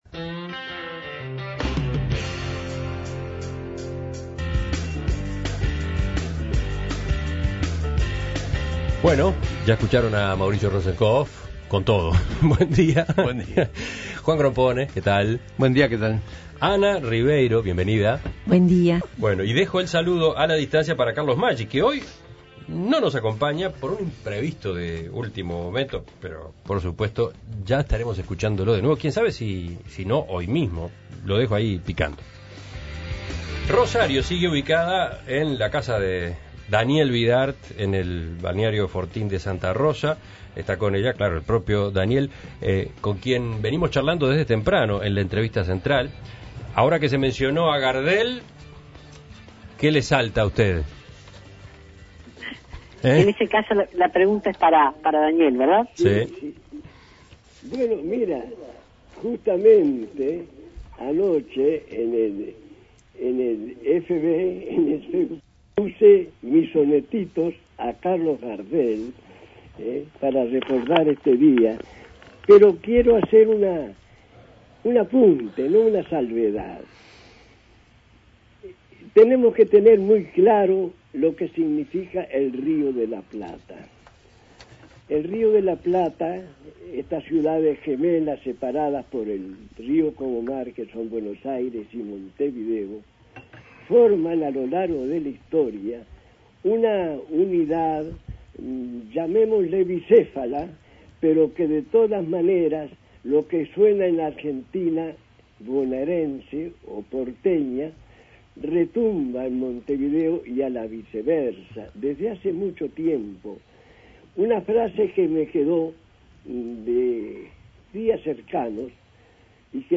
Daniel Vidart en conversación con Ana Ribeiro, Mauricio Rosencof y Juan Grompone.